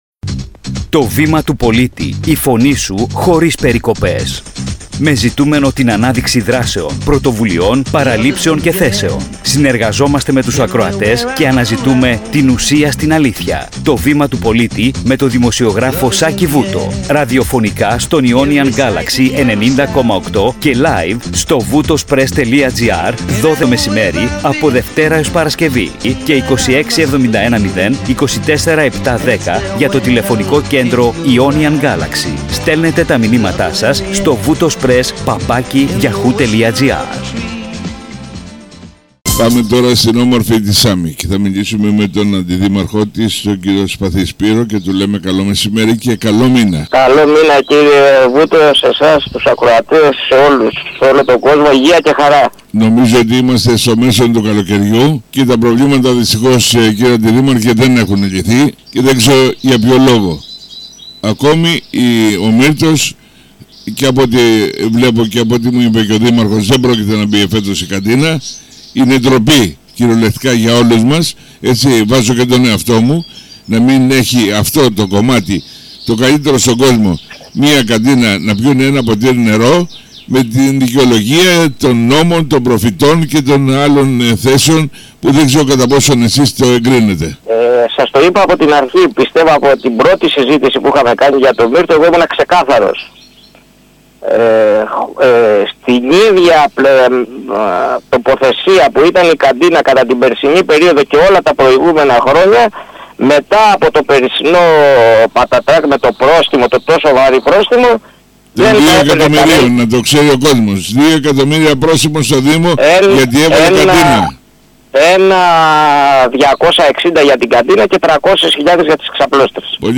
ΚΥΡΙΑ ΘΕΜΑΤΑ ΤΗΣ ΣΥΝΕΝΤΕΥΞΗΣ